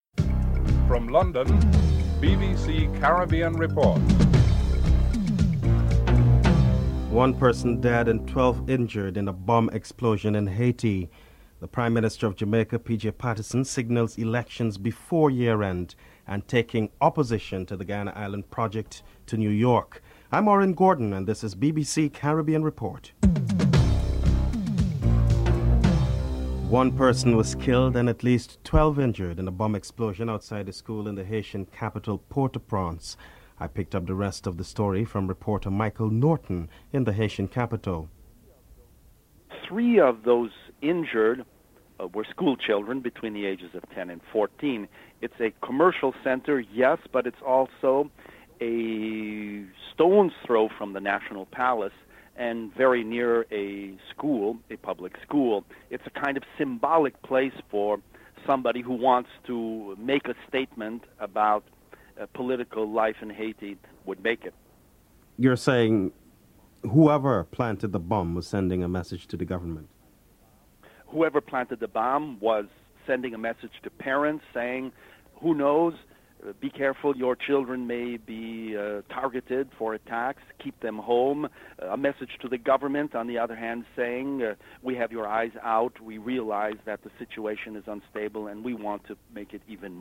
The British Broadcasting Corporation
1. Headlines (00:00-00:25)
4. The Prime Minister of Jamaica P.J. Patterson signals elections before the year ends. Prime Minister P. J. Patterson and Lawyer Johnnie Cochran are interviewed (05:18-06:51)